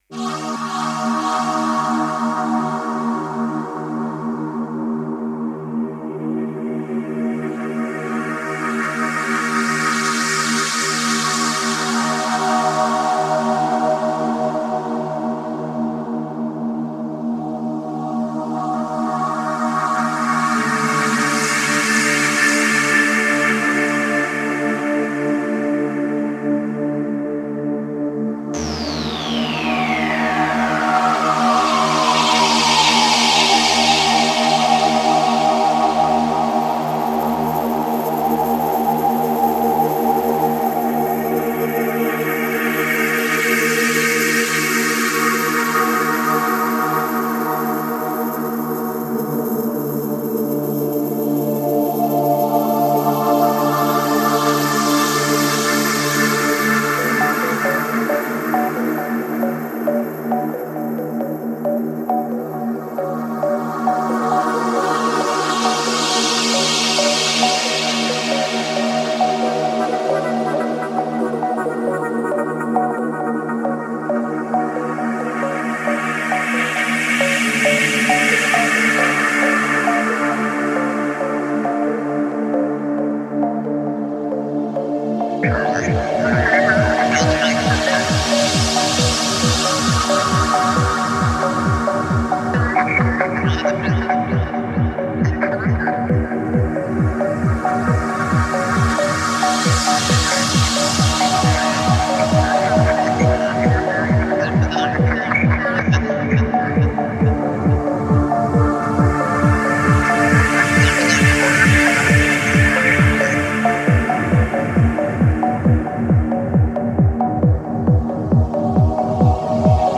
lofiHipHop